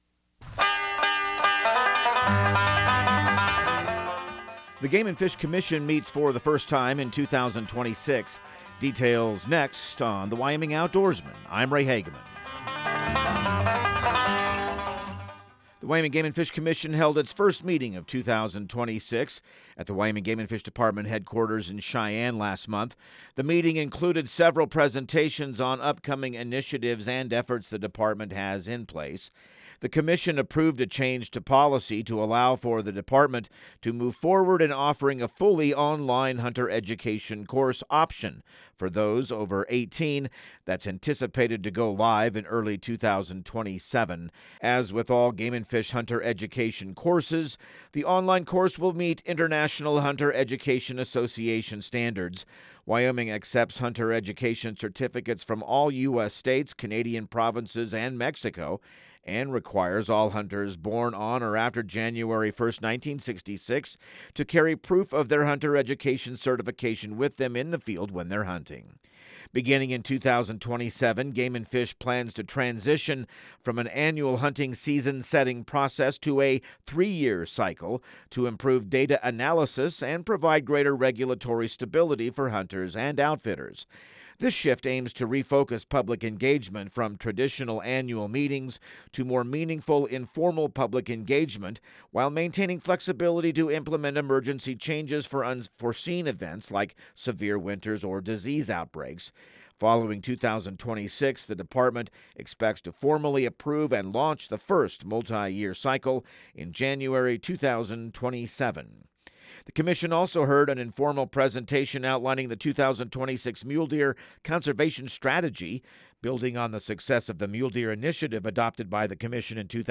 Radio news | Week of February 2